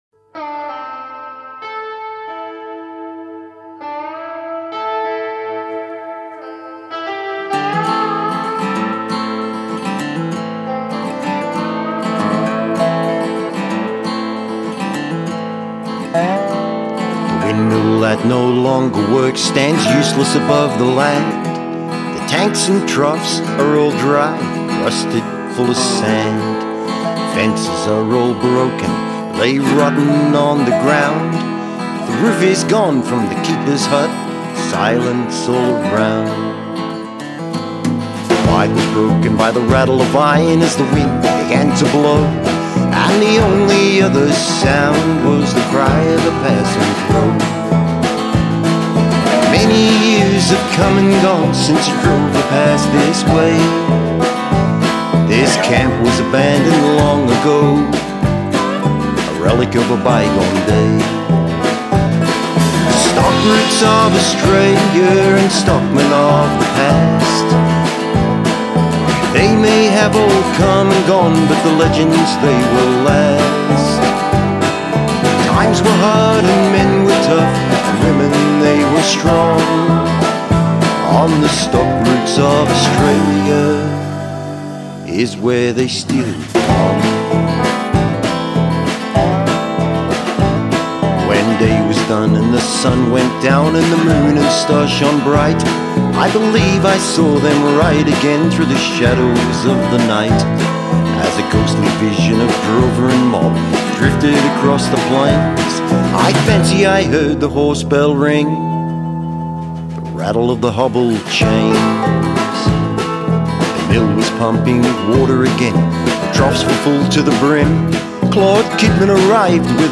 Acoustic Guitar
Dobro, Drums & Bass
Recorded at MonoNest studio